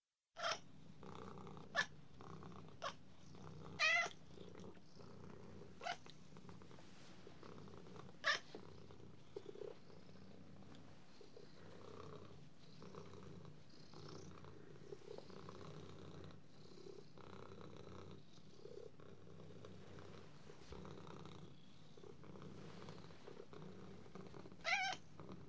Cat Squealing And Purring